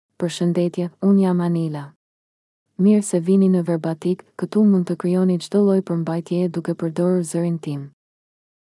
FemaleAlbanian (Albania)
Anila is a female AI voice for Albanian (Albania).
Voice sample
Female
Anila delivers clear pronunciation with authentic Albania Albanian intonation, making your content sound professionally produced.